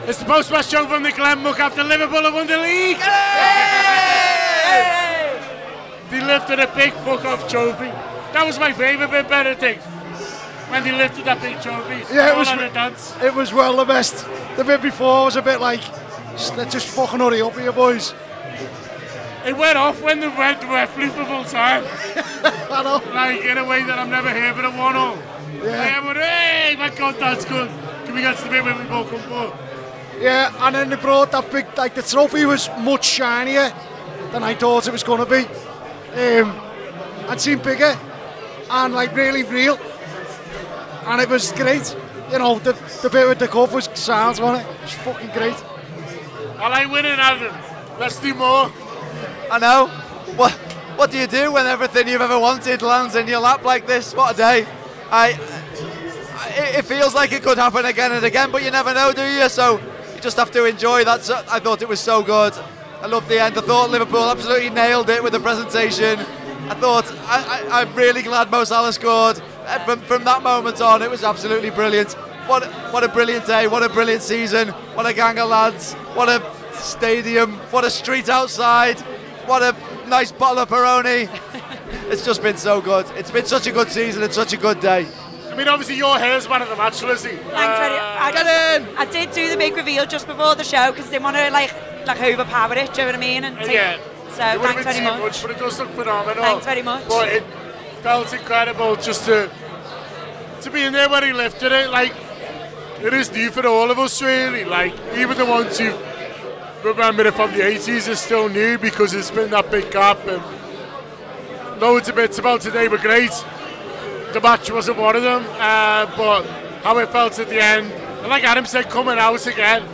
Liverpool 1-1 Crystal Palace: Post-Match Show Reaction Podcast